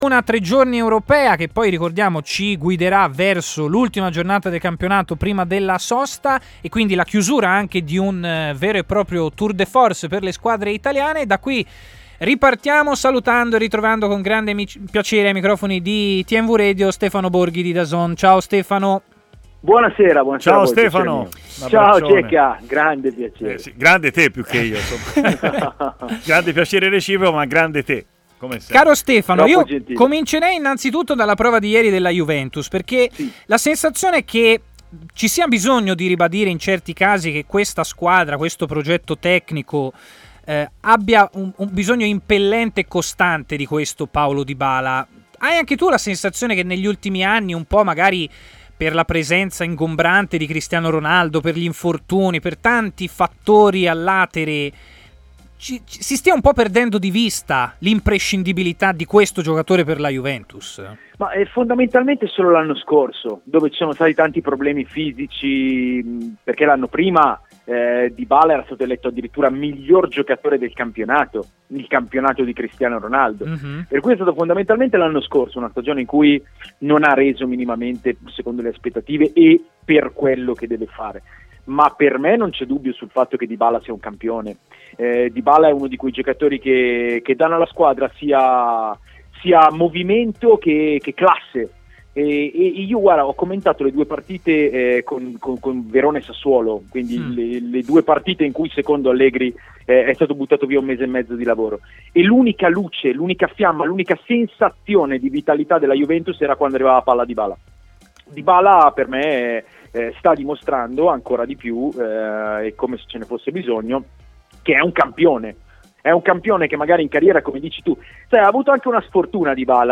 è intervenuto in diretta durante Stadio Aperto, trasmissione di TMW Radio
L'intervista